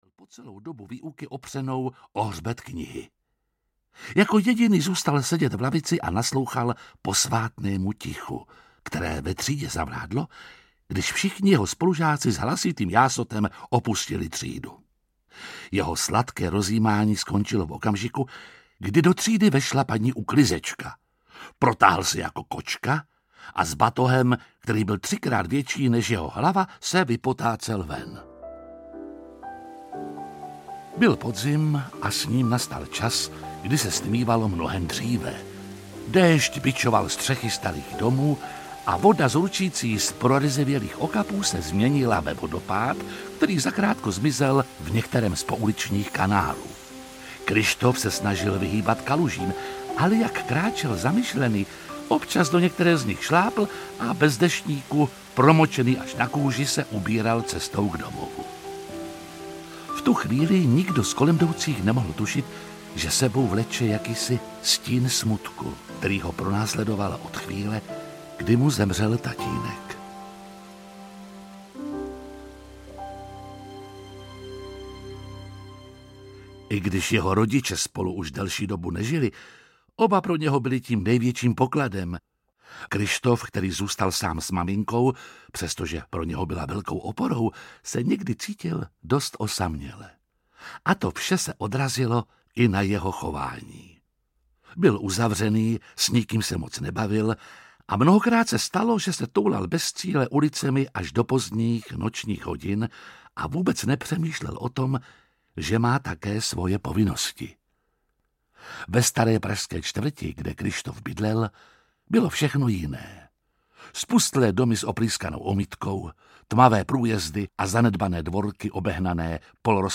Kryštofova myš audiokniha
Ukázka z knihy